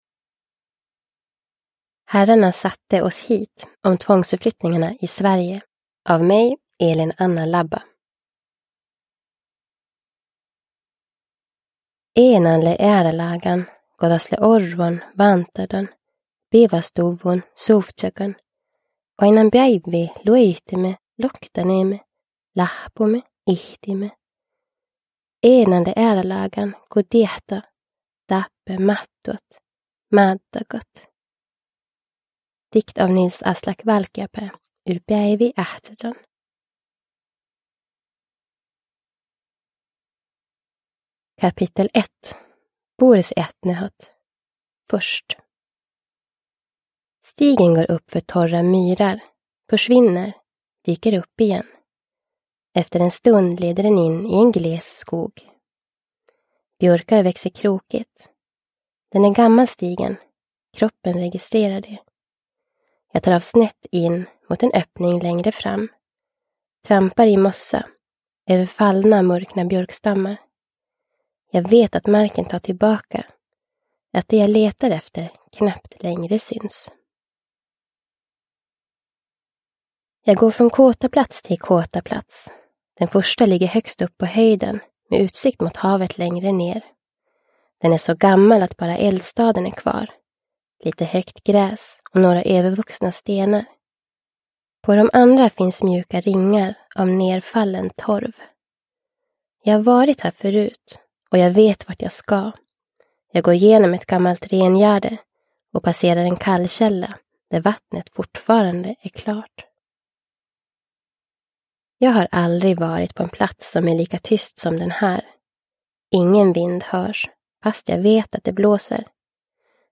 Herrarna satte oss hit : om tvångsförflyttningarna i Sverige – Ljudbok – Laddas ner